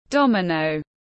Quân cờ đô-mi-nô tiếng anh gọi là domino, phiên âm tiếng anh đọc là /ˈdɒm.ɪ.nəʊ/